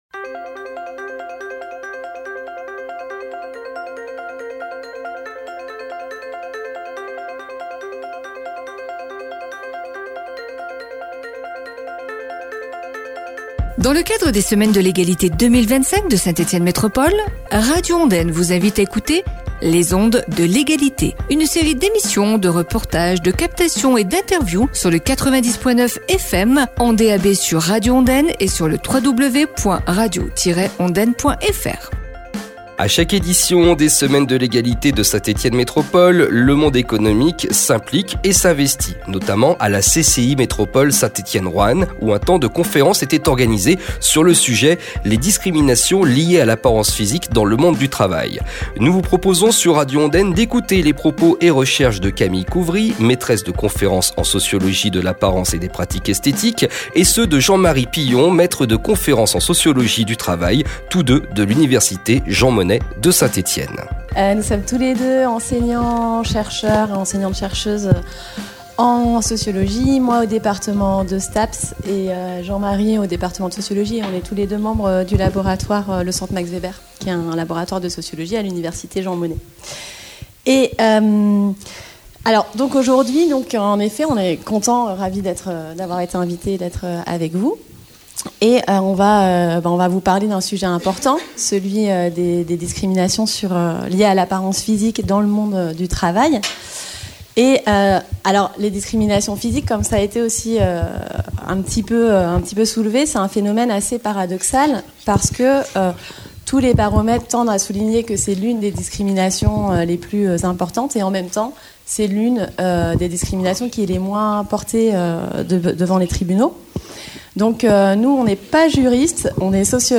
A la CCI Métropole Saint-Etienne-Roanne, le 13 Mars, deux enseignants chercheurs étaient invités autour du thème » Les discriminations liées à l’apparence physique dans le monde du travail.